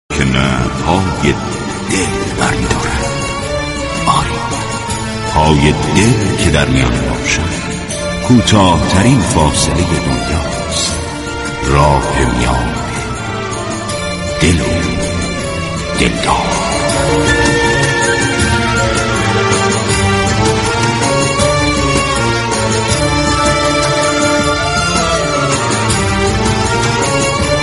به گزارش روابط عمومی رادیو صبا ، دل و دلدار عنوان ویژه برنامه زنده عصرگاهی است كه به مناسبت ایام پایانی ماه صفر راهی آنتن صبا می شود.
این برنامه با بخش های متنوع «مهمان ویژه » ، « گفتگوی تلفنی » و آیتم های « قصه عشق »، « یك قطره عشق » ، « تنلنگر » و «گزارش » در سالروز شهادت امام رضا (ع ) تهیه شده وتقدیم مخاطبان می شود .